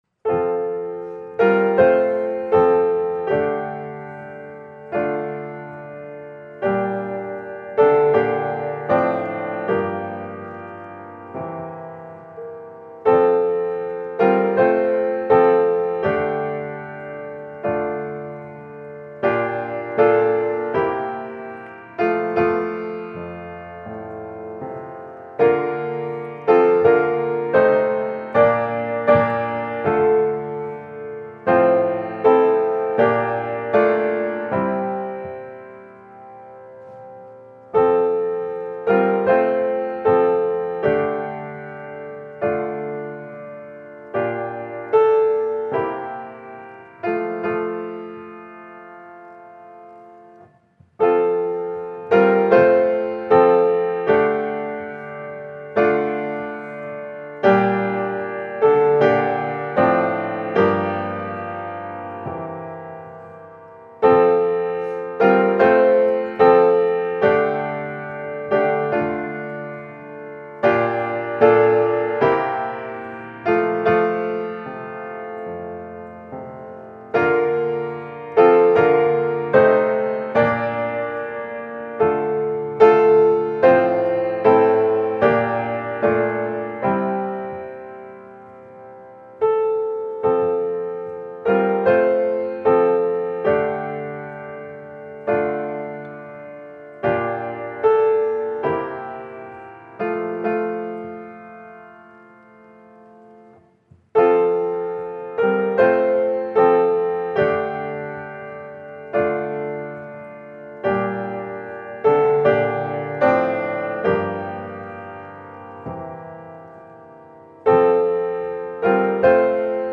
Prelude, Bells, Advent Litany, Welcome, Prayer of the day: